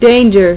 1 channel
snd_145_danger.mp3